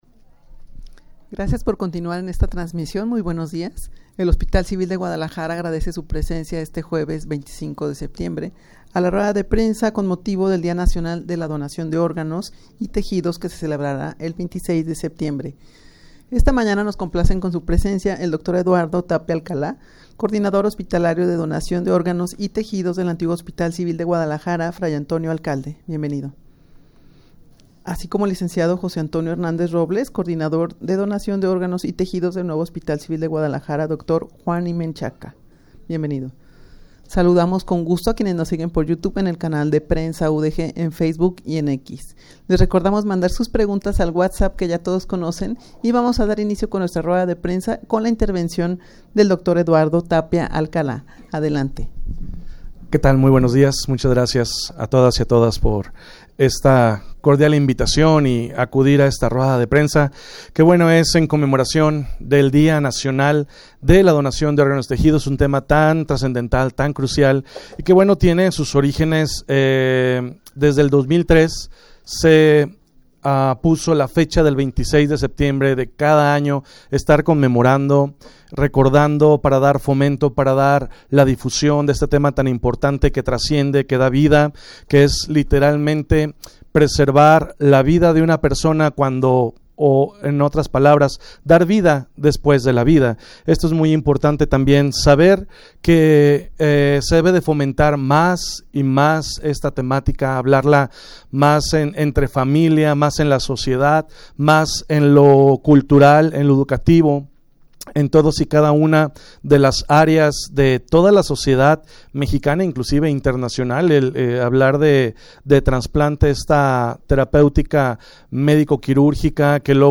Audio de la Rueda de Prensa
rueda-de-prensa-con-motivo-del-dia-nacional-de-la-donacion-de-organos-y-tejidos-.mp3